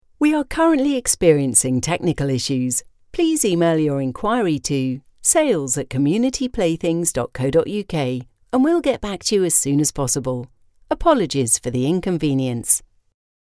Engels (Brits)
Commercieel, Warm, Veelzijdig, Vriendelijk, Zakelijk
Telefonie